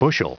Prononciation du mot bushel en anglais (fichier audio)
Prononciation du mot : bushel